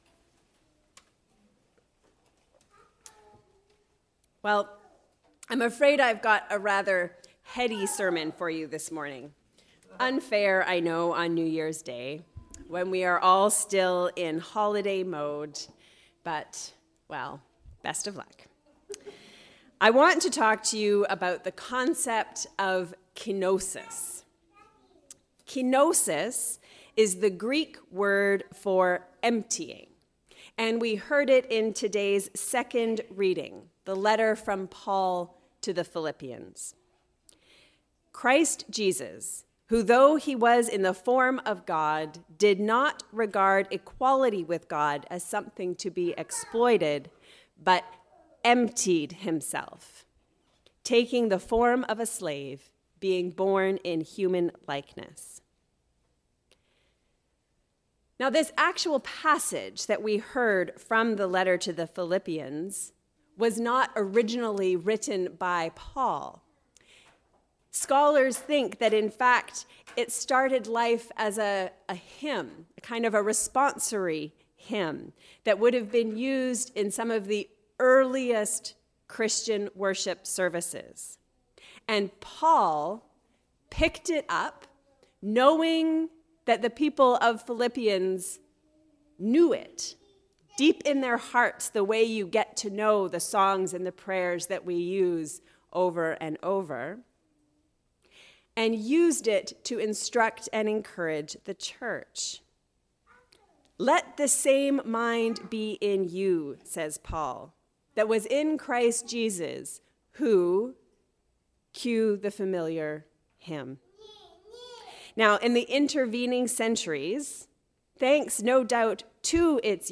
Let the same mind be in you… A sermon on Philippians 2:5-11